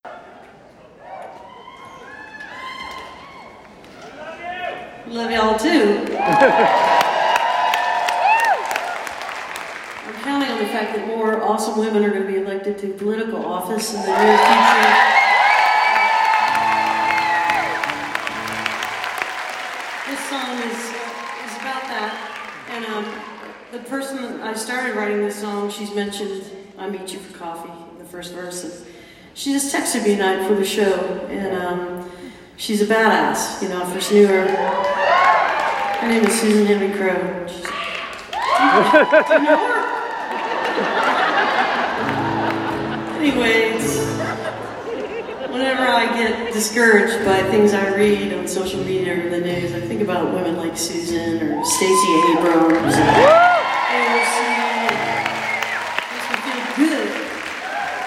11. talking with the crowd (0:58)